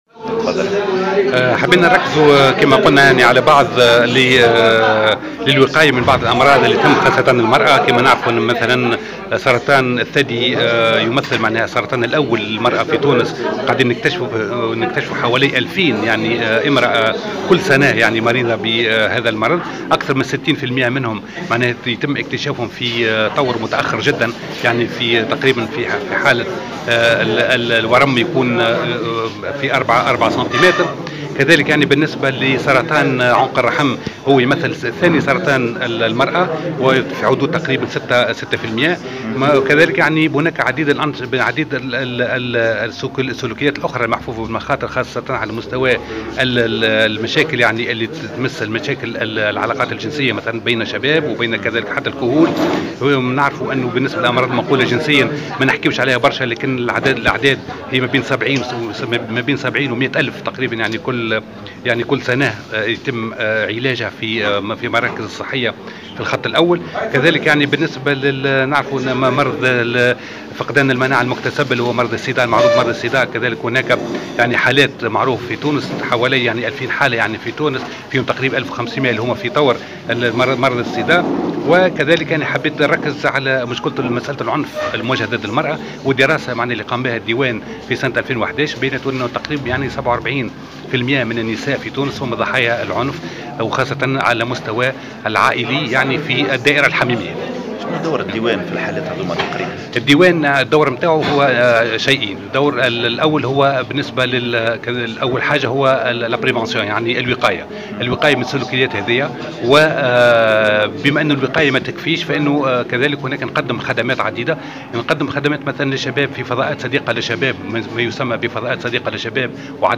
كشف رئيس مدير عام الديوان الوطني للأسرة والعمران البشري رضا بوڨطعة خلال تظاهرة صحية تحسيسية انتظمت اليوم الجمعة 15 ماي 2015 بالمنستير بمناسبة الإحتفال باليوم العالمي للأسرة أنه يتم تسجيل 2000 حالة إصابة بسرطان الثدي كل سنة في صفوف النساء و أن أكثر من 60% منها يتم اكتشافها في طور متأخر جدا.
تصريح